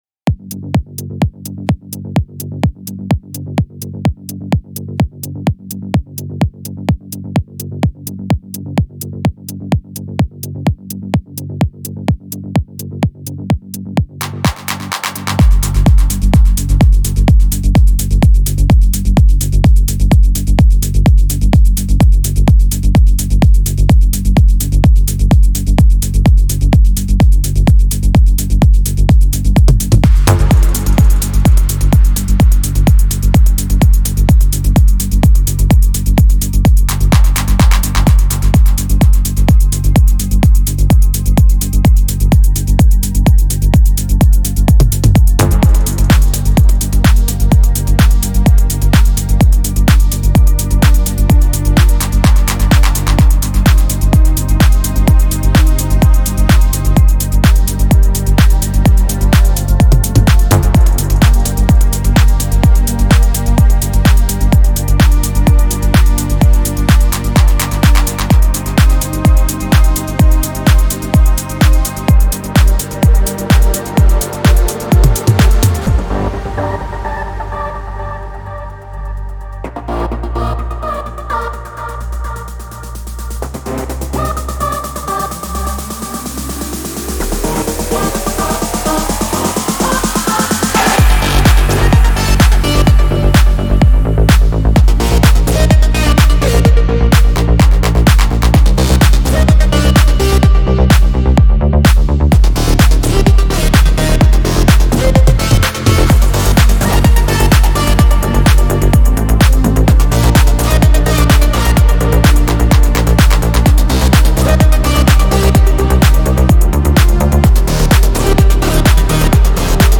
• Жанр: Techno